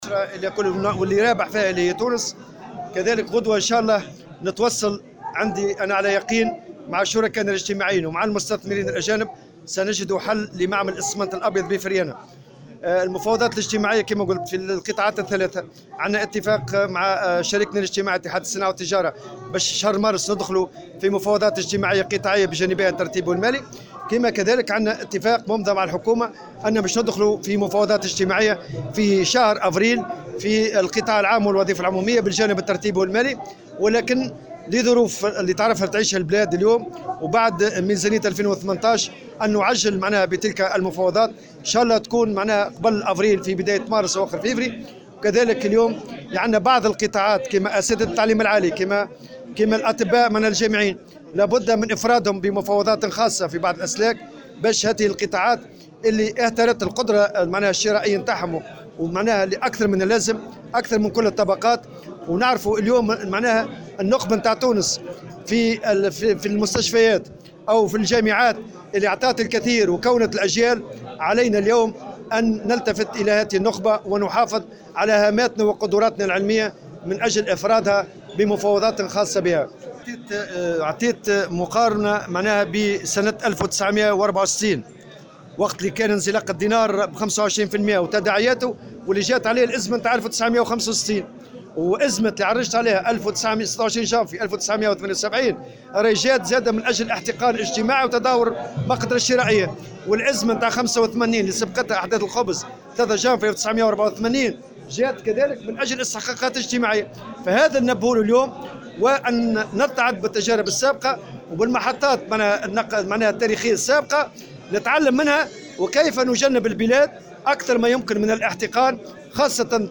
على هامش أشغال مؤتمر الجامعة العامة للنفط والمواد الكيمياوية، اليوم الخميس في الحمامات